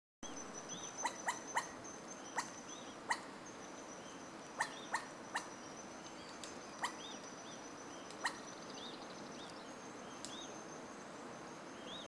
Black-throated Huet-huet (Pteroptochos tarnii)
Life Stage: Adult
Location or protected area: Parque Nacional Lanín
Condition: Wild
Certainty: Observed, Recorded vocal
Huet-huet.mp3